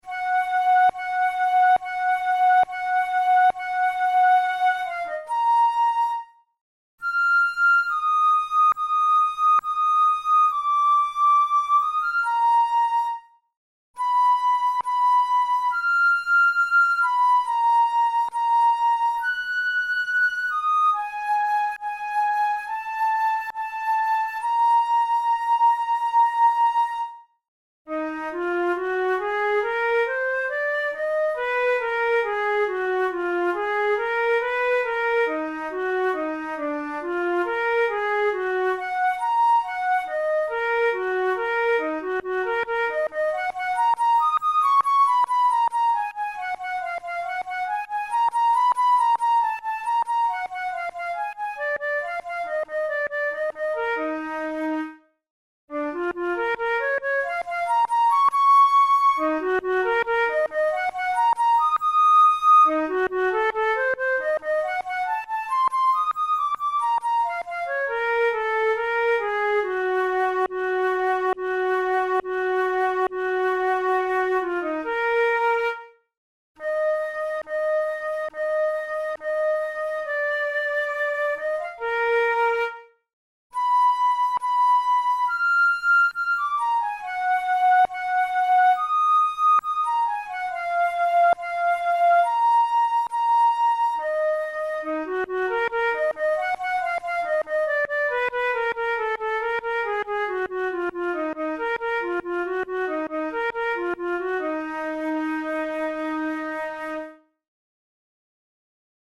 Categories: Etudes Romantic Written for Flute Difficulty: intermediate
drouet-methode-pour-la-flute-study-no20.mp3